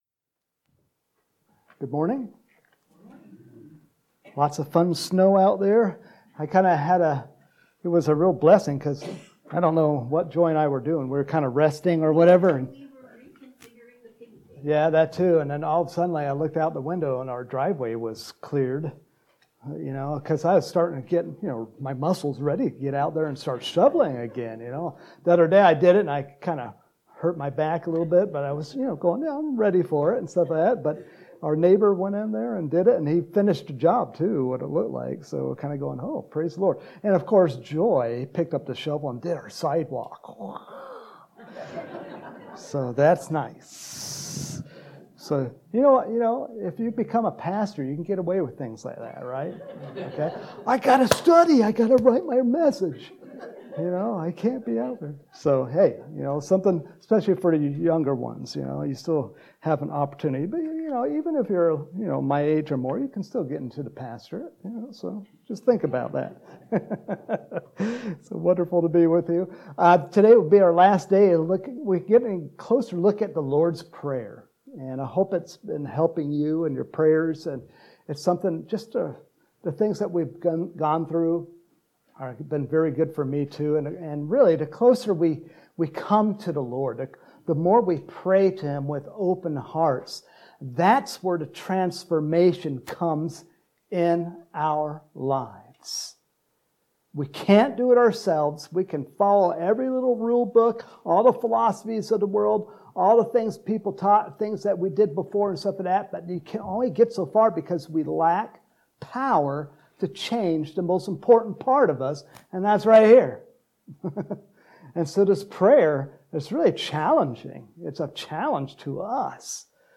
Home › Sermons › March 13, 2022